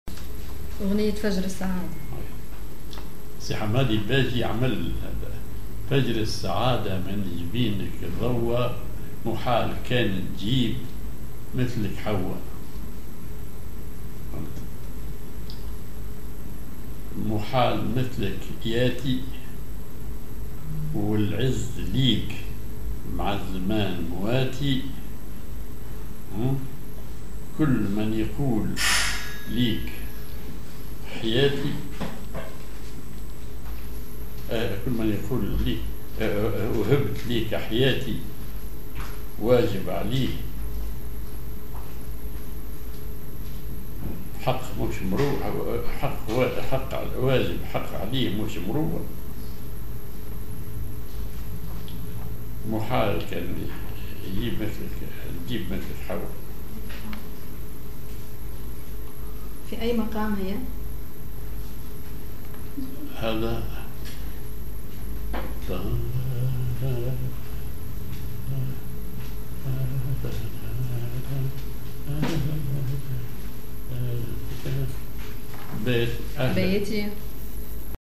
Maqam ar عراق تونسي
genre أغنية